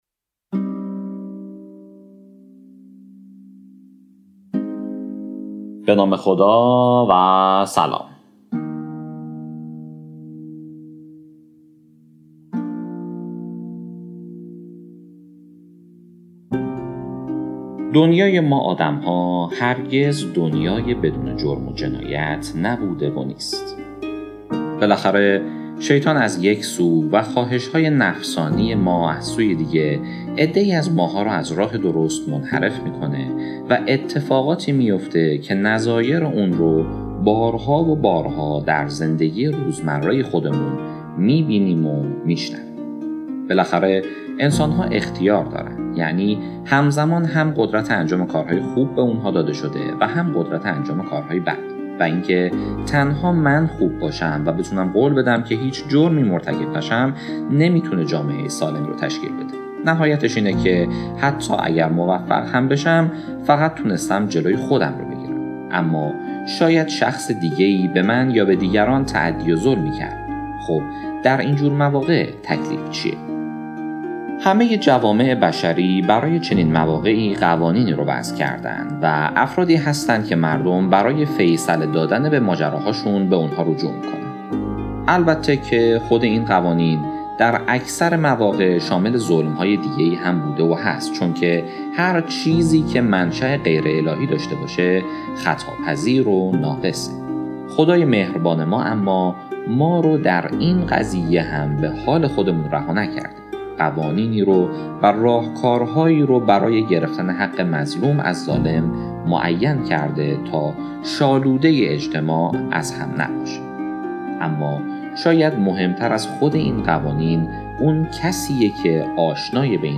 پادکست صوتی داستان هفته شصت و هفتم